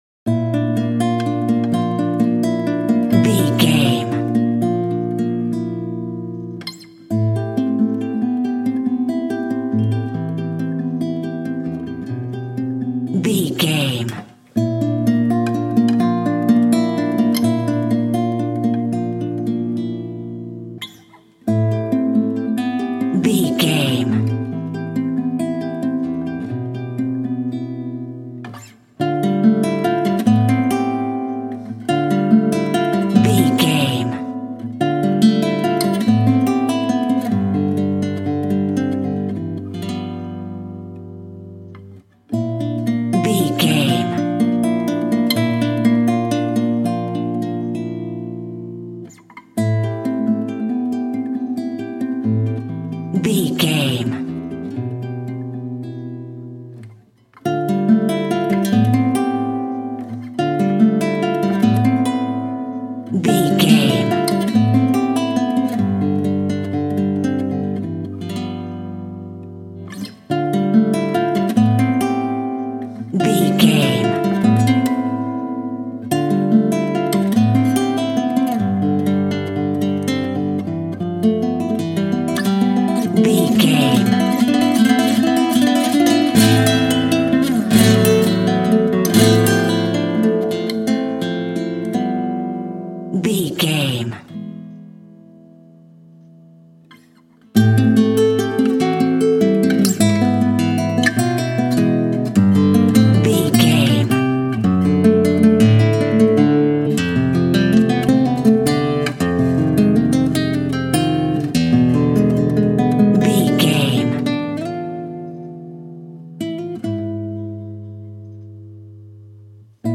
Good times, relaxing, friends, romantic, dreamy
Aeolian/Minor
acoustic guitar